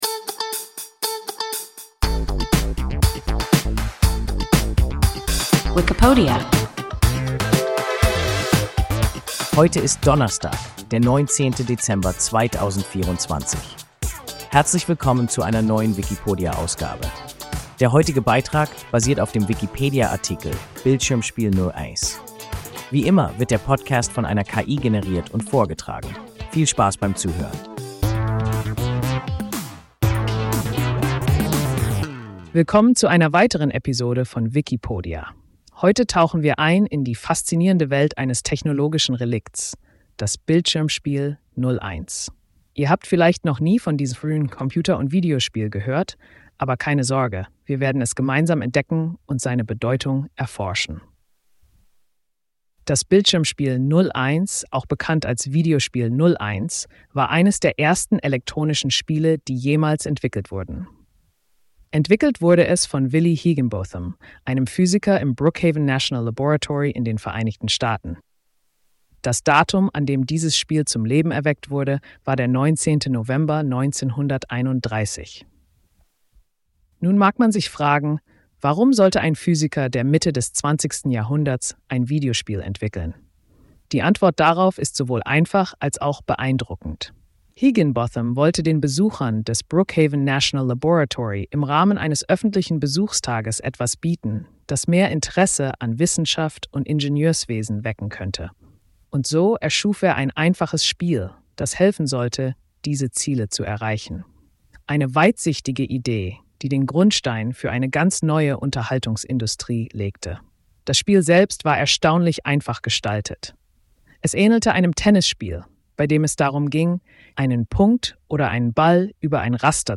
Bildschirmspiel 01 – WIKIPODIA – ein KI Podcast